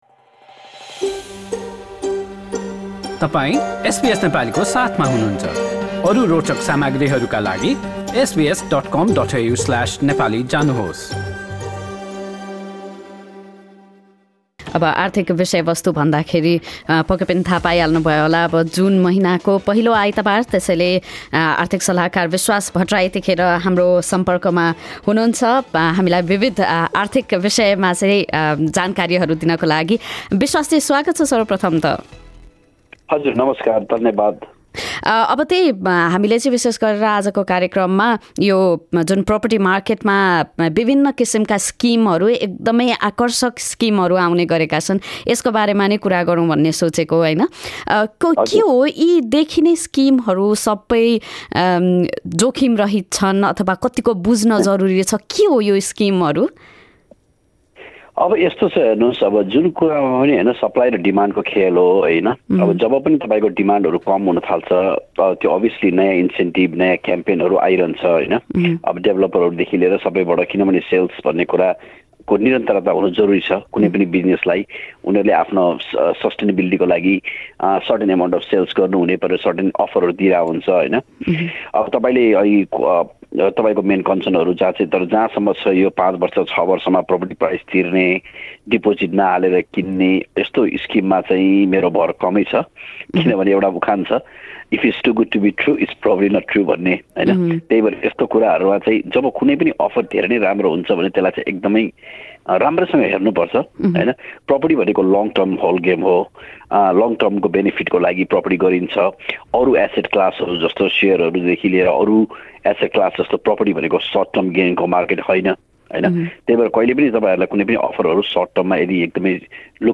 आर्थिक कुराकानी हरेक महिनाको पहिलो आइतवार अपराह्न ४ बजेको कार्यक्रममा प्रत्यक्ष प्रसारण हुन्छ।